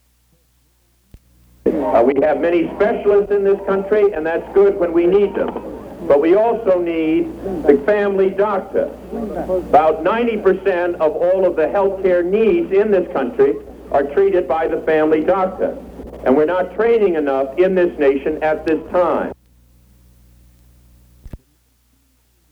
Massachusetts Senator Ted Kennedy tells an audience that America is still training too few general practitioners